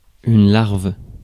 Ääntäminen
Synonyymit lémures Ääntäminen France: IPA: /laʁv/ Haettu sana löytyi näillä lähdekielillä: ranska Käännös Konteksti Ääninäyte Substantiivit 1. larva US 2. grub eläintiede US 3. maggot Suku: f .